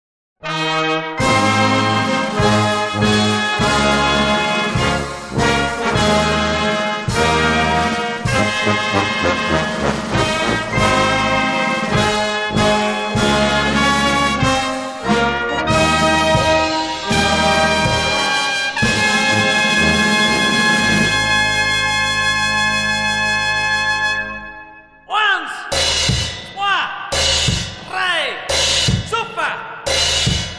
traditional Bavarian folk music